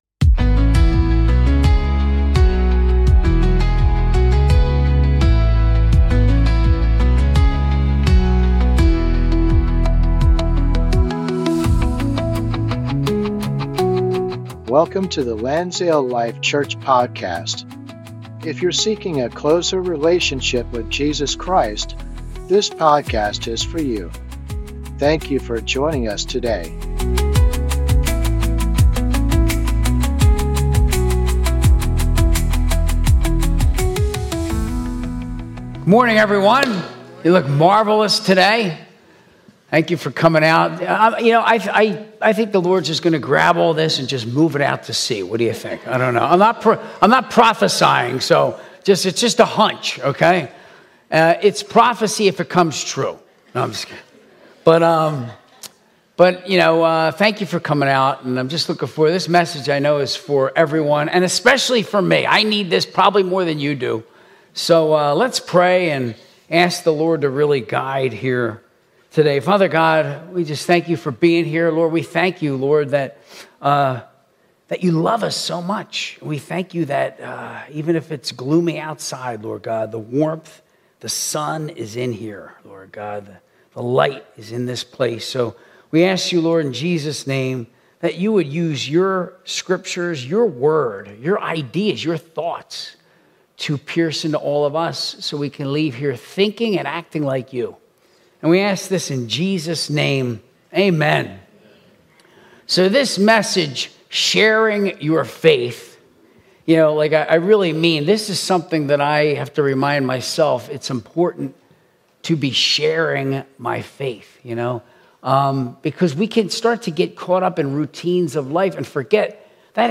Sunday Service 2026-02-22